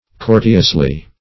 Courteously \Cour"te*ous*ly\, adv.
courteously.mp3